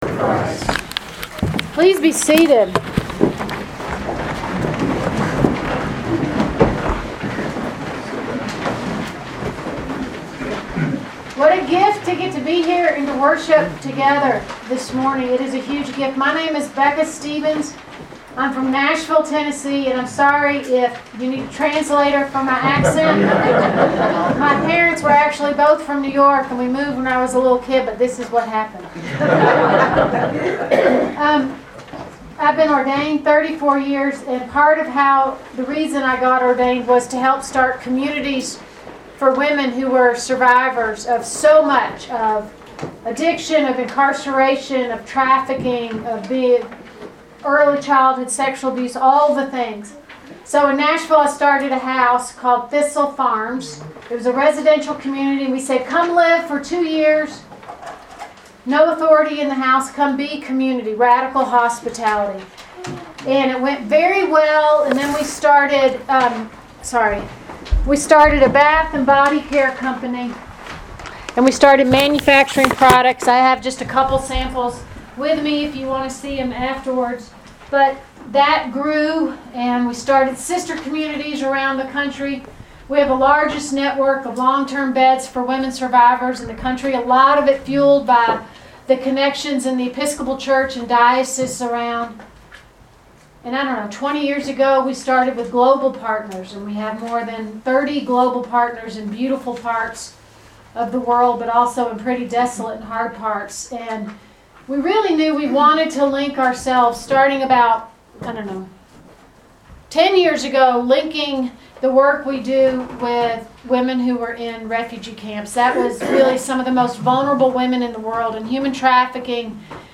Proper 13 at Chapel of the Transfiguration
Sermons from St. John's Episcopal Church